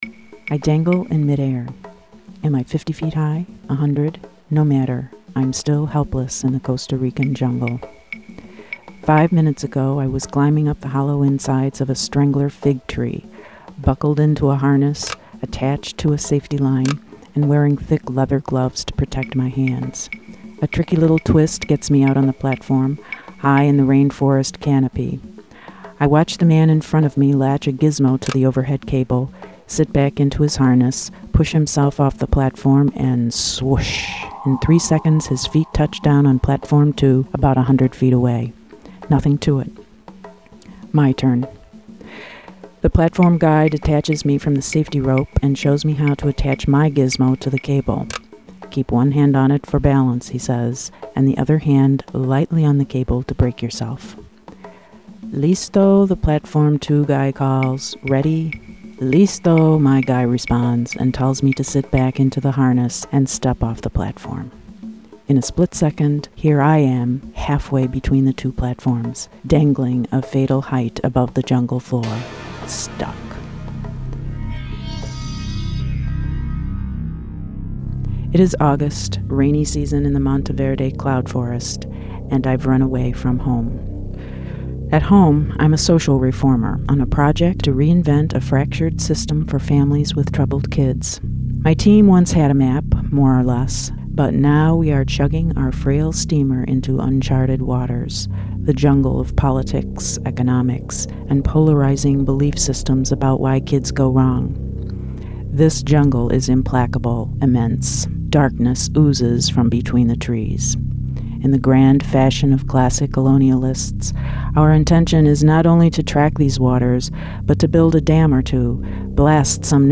Equipment: Marantz minidisc recorder; Sony ECM-MS907 condenser mic
Software: Sony Media SoundForge for editing; Sony Media Acid Pro for composing and mixing musical highlights, using royalty-free loops.
The voice is nicely braided with sounds that place the musings in a very real physical place, where the singular problem to be solved happens to mirror the labyrinth that the writer brought to the jungle.   The degree of complexity in the voice/sound mix is just right.